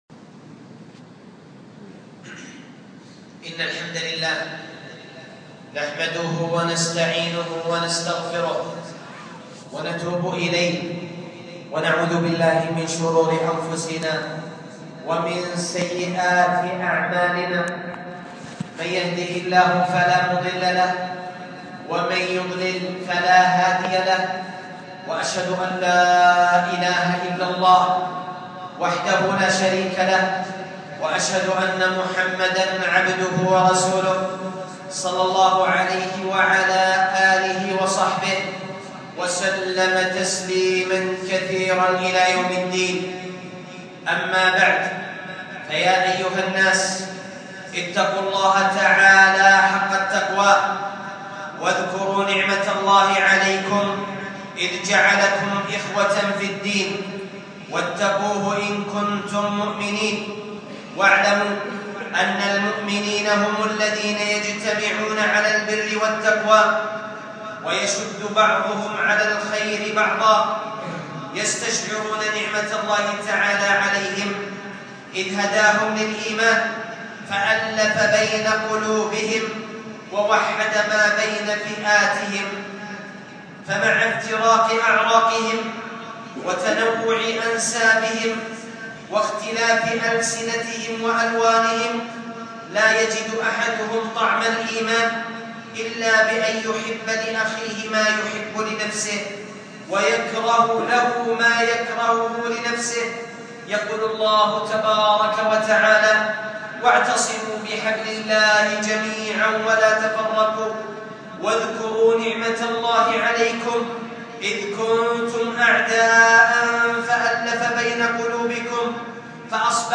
خطبة عن قيادة المرأة للسيارة